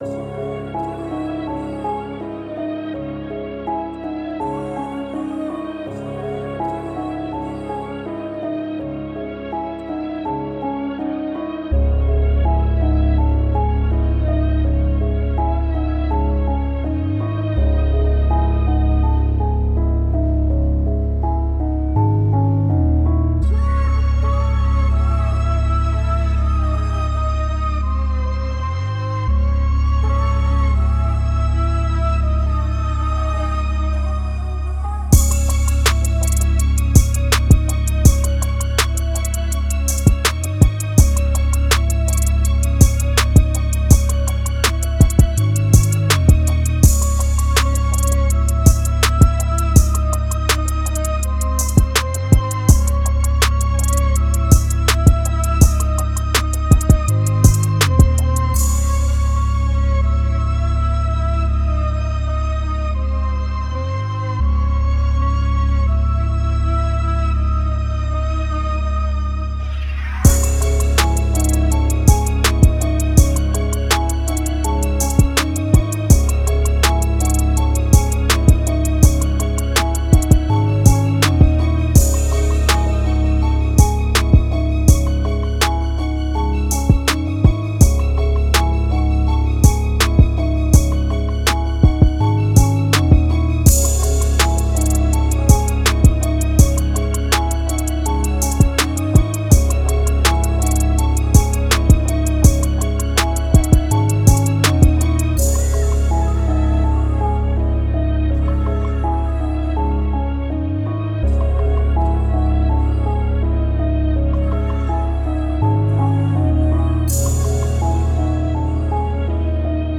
is a dark, cinematic trap instrumental
• Dark Cinematic Trap Style
• Hard 808s & Punchy Drums
• Emotional Piano & Atmospheric Melodies